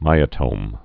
(mīə-tōm)